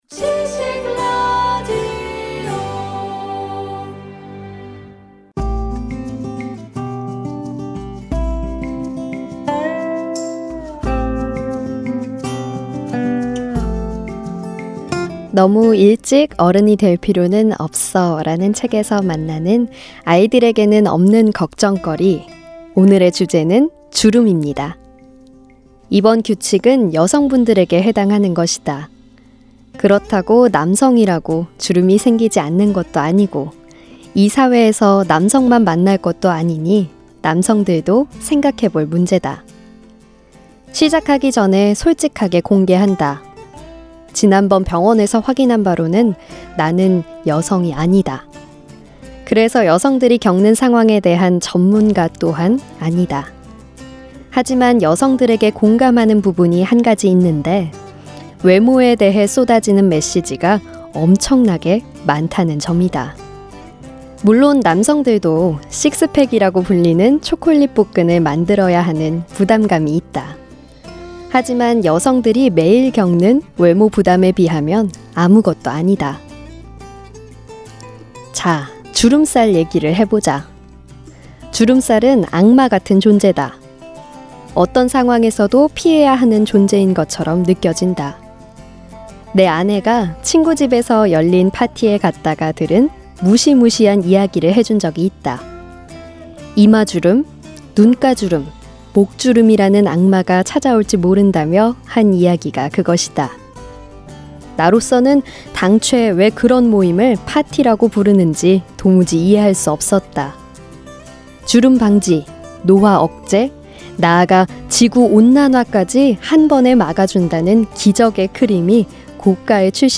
북 큐레이터